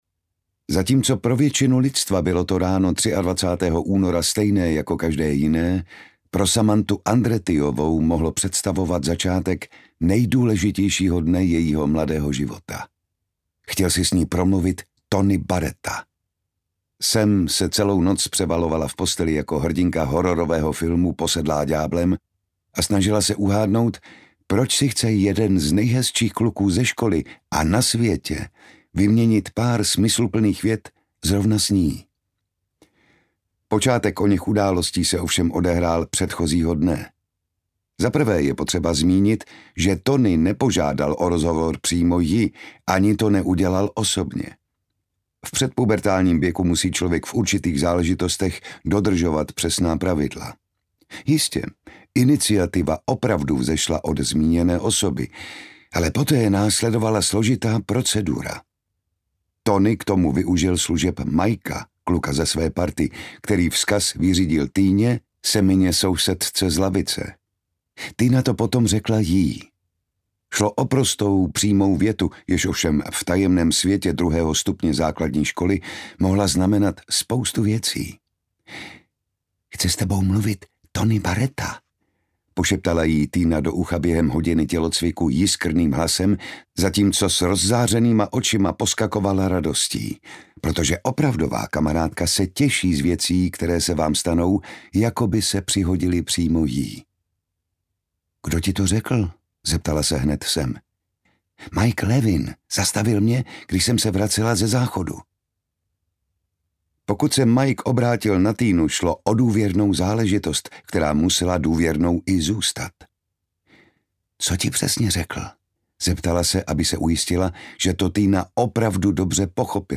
Ukázka z knihy
• InterpretJan Šťastný